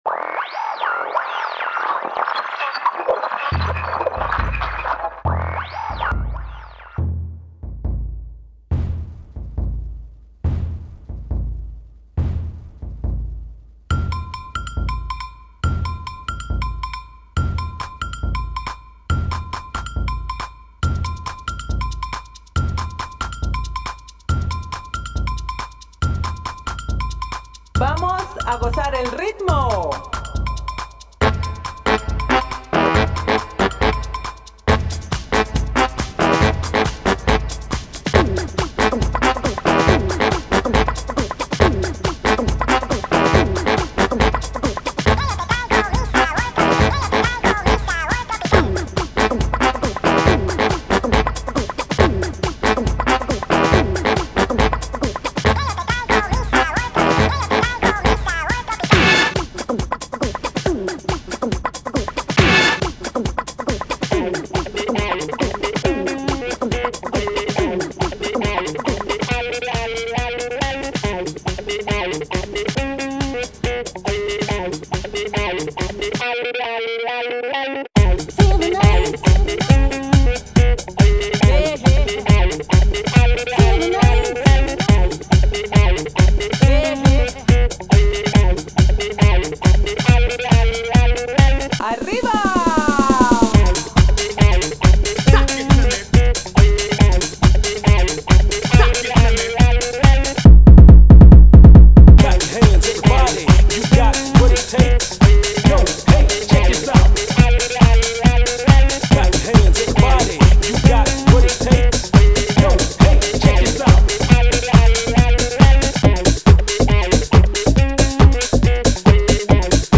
Правда абстрактный трэк, а так молодец, мне понравилось)